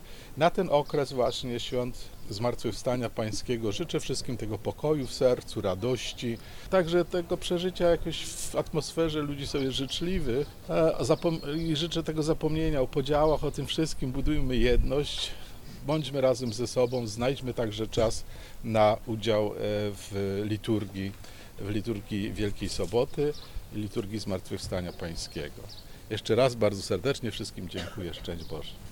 Na koniec, arcybiskup złożył życzenia wielkanocne.